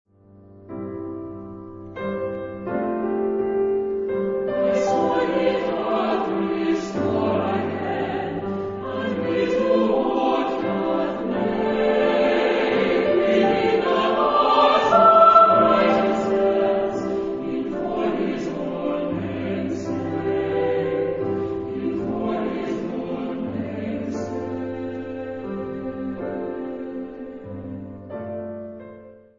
Genre-Style-Form: Sacred ; Motet ; Psalm
Mood of the piece: expressive
Type of Choir: SAH  (3 mixed voices )
Instrumentation: Piano  (1 instrumental part(s))
Tonality: C major